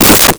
Wood Crack 01
Wood Crack 01.wav